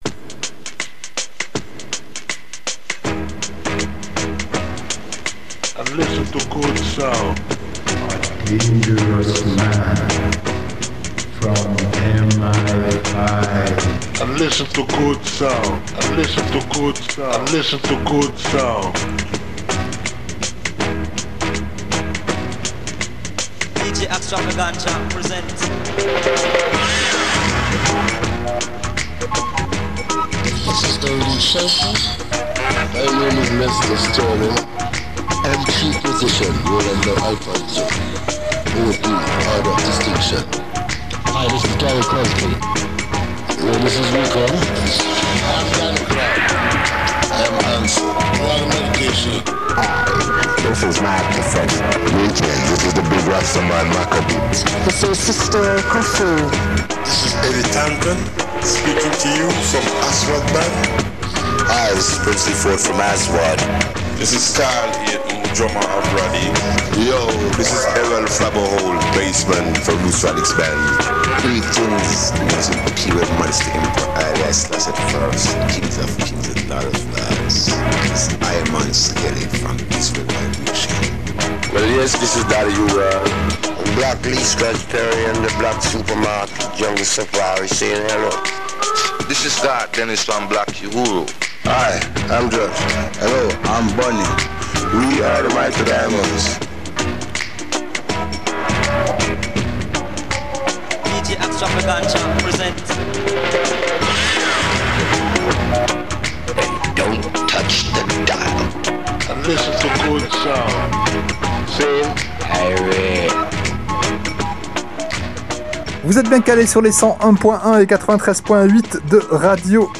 radio show !
ska rocksteady